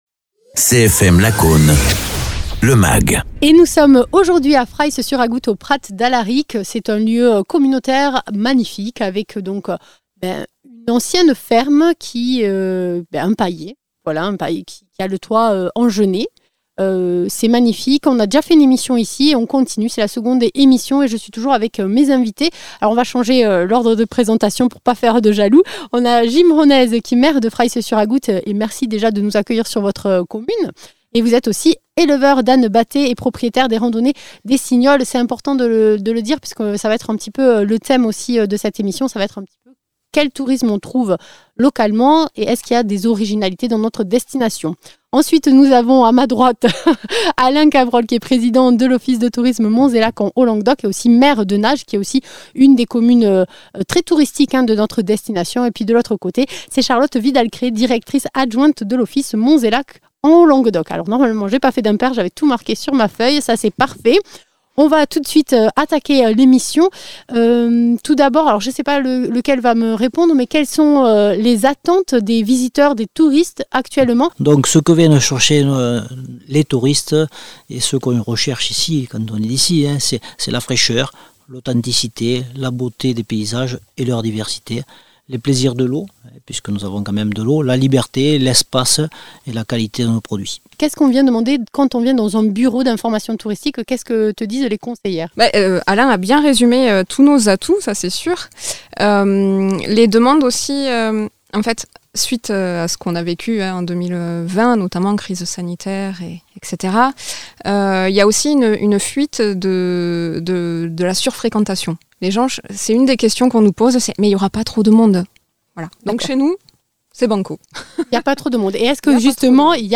Fraïsse-sur-Agoût (Hérault) et plus exactement le Prat d’Alric est le lieu que nous avons choisi pour parler tourisme et plus exactement de la singularité de la destination du Tourisme Monts & Lacs en Haut-Languedoc (Tarn et Hérault).
Interviews